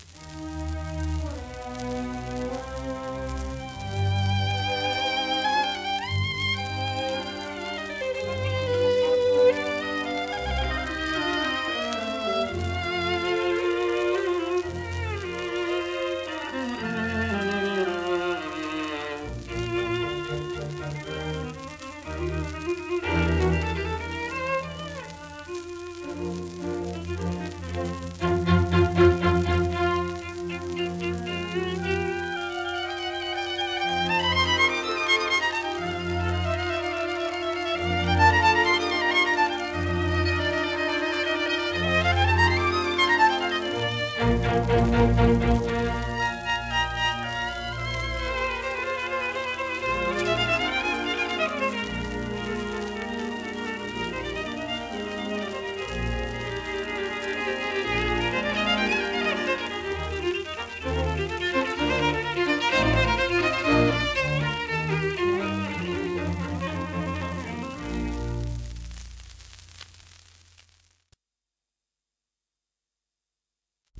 アルバート・サモンズ(Vn:1886-1957)
&ライオネル・ターティス(va:1876-1975)
w/H.ハーティ(cond)、ロンドン響
1933年録音
英国を代表するヴァイオリン奏者のひとりであるサモンズと、ヴィオラ独奏パイオニアの一人ターティスによる共演盤
シェルマン アートワークスのSPレコード